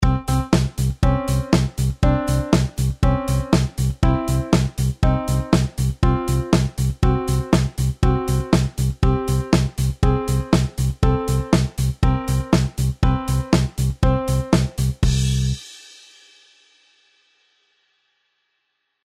А если мы будем нажимать на клавиши одновременно, то мы создадим аккорд или гармонию, поэтому такой интервал называют "Гармоническим."
Как вы могли заметить, что Чистая Прима (нота ДО), в первом такте, играется два раза, а все потому, что интервал - это расстояние между двумя звуками.
harmonic_intervals.mp3